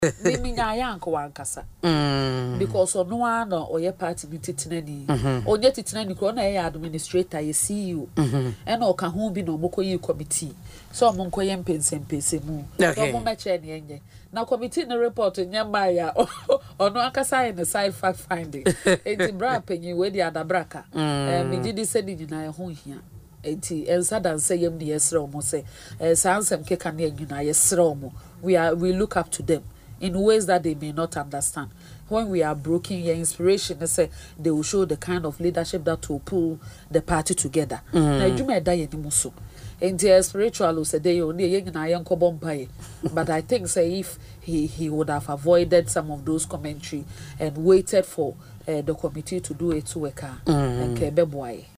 However, Fatimatu disagreed in an interview on Asempa FM’s Ekosii Sen show.